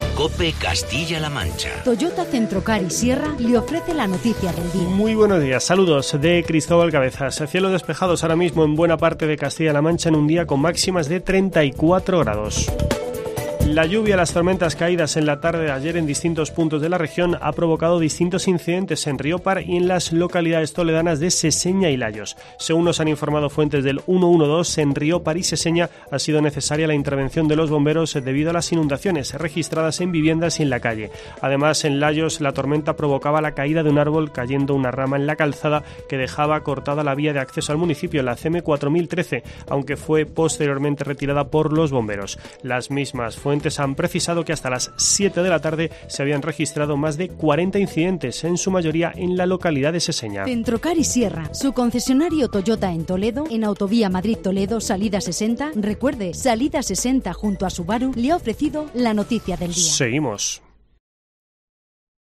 Boletín informativo de COPE Castilla-La Mancha.